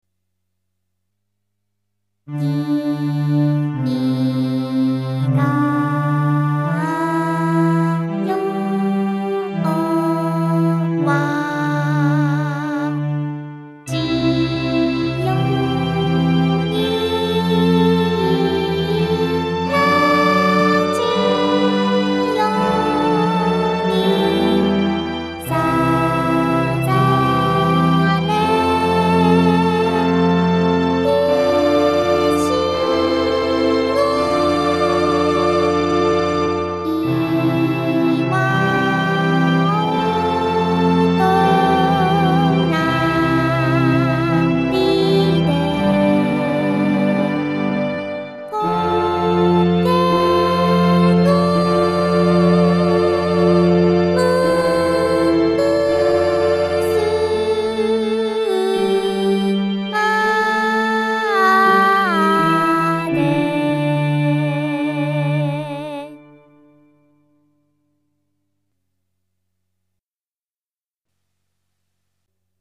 第一スタジオ（コントロールルームのみ）　21.00〜21.45 pm テープ・コピー「君が代」、ステレオミキシング、ボーカルトラック
外部キーボードより録音した。
アレンジはＮＨＫ放送終了の音楽を耳コピしたものである。初々しいミクのボーカルは胸にしみる。